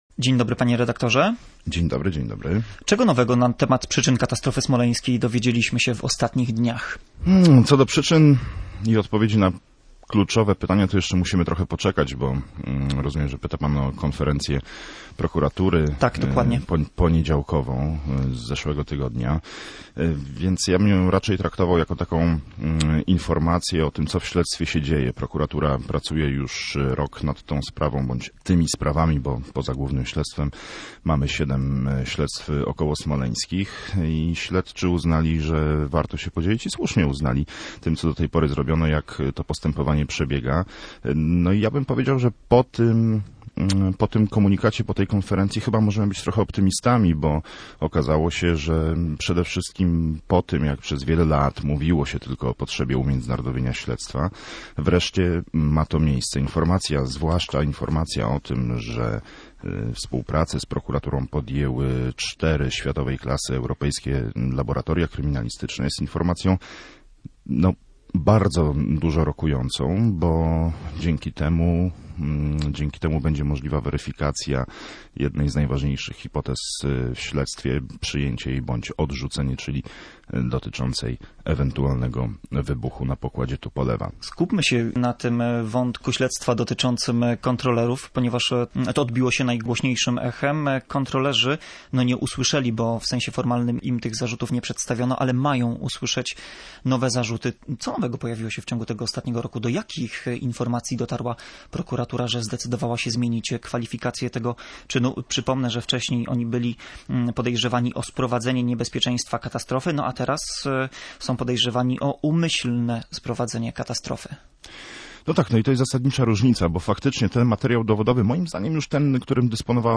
„Pogrzebana Prawda”. Nasz reporter rozmawiał z dziennikarzem, badającym katastrofę smoleńską